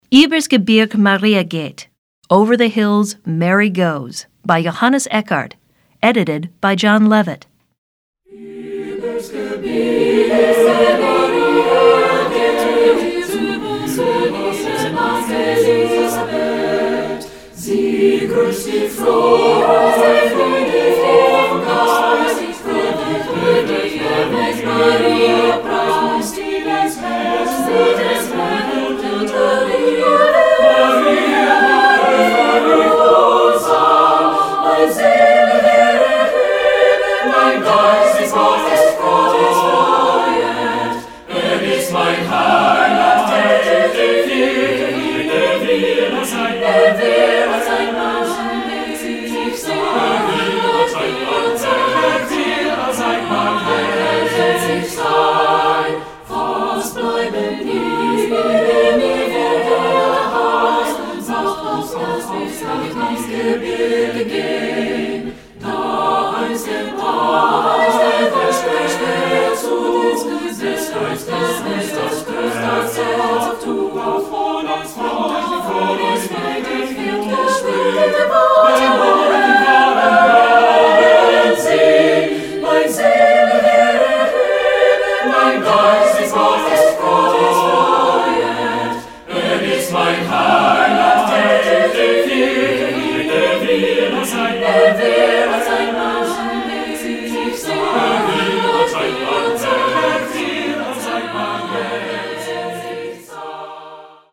Voicing: SSATB a cappella